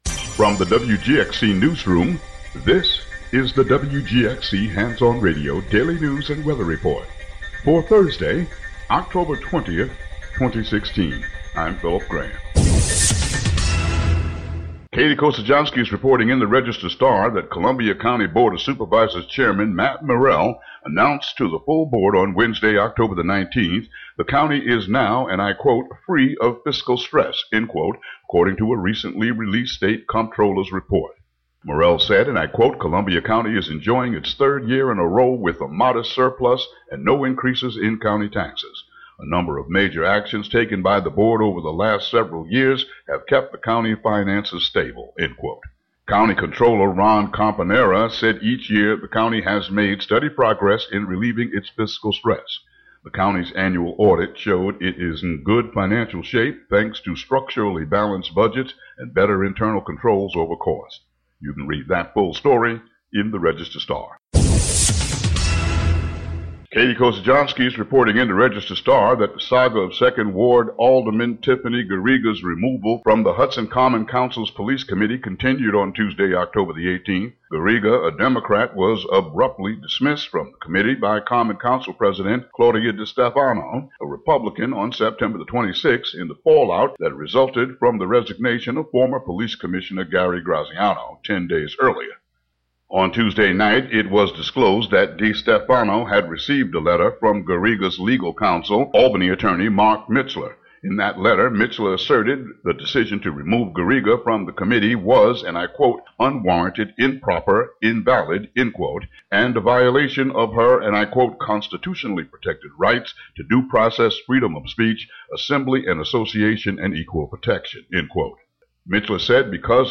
WGXC daily headlines and weather.